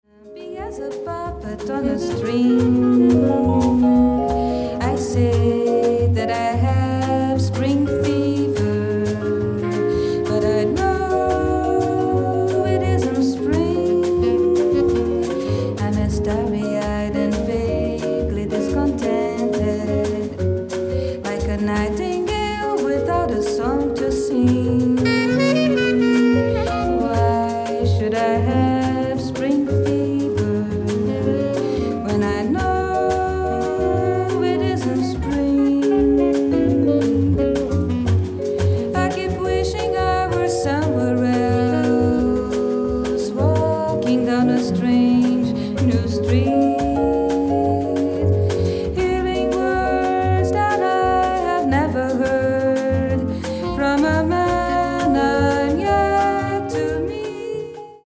ライブ・アット・フィルハーモニー、ベルリン、ドイツ 11/04/1966
※試聴用に実際より音質を落としています。